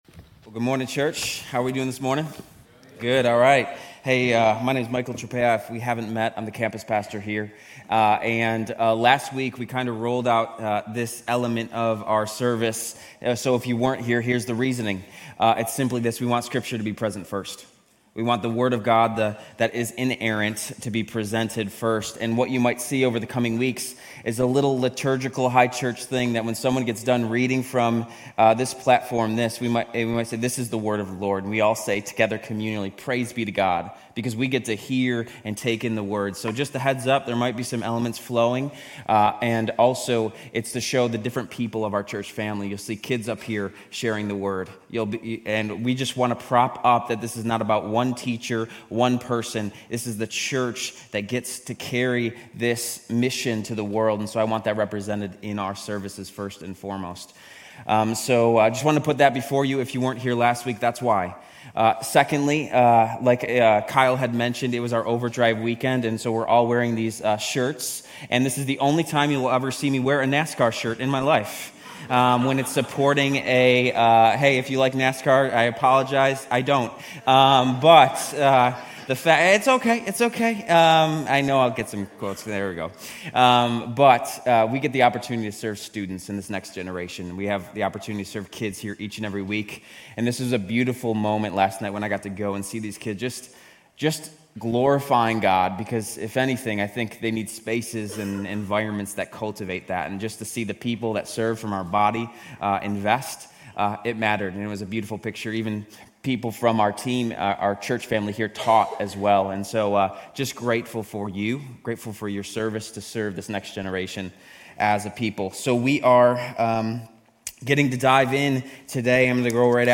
Grace Community Church University Blvd Campus Sermons 2_2 University Blvd Campus Feb 03 2025 | 00:32:48 1x 00:00 / 00:32:48 Subscribe Share RSS Feed Share Link Embed